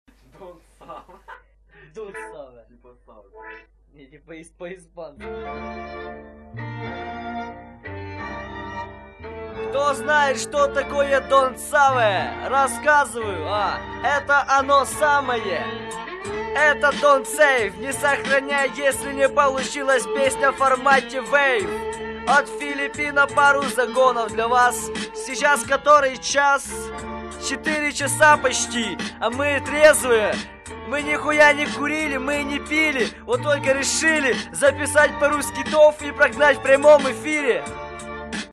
Музыкальный хостинг: /Рэп
freestyle